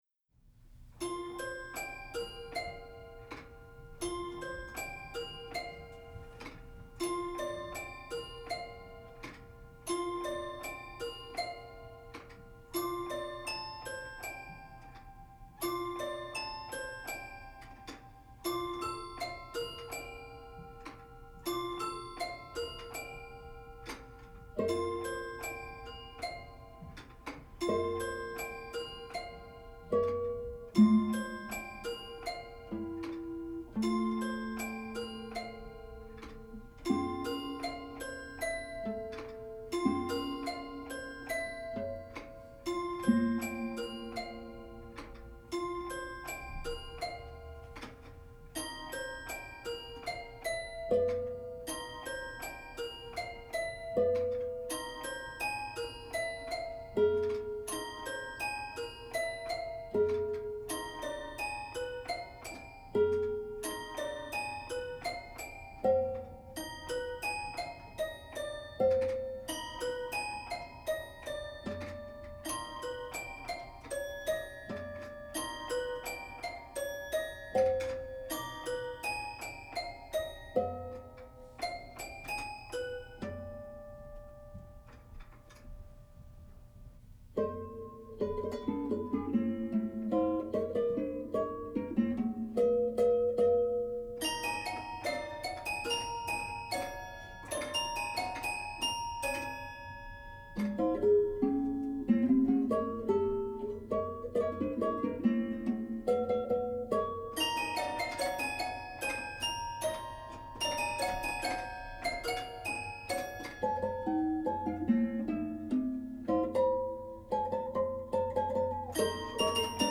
for prepared guitar and toy piano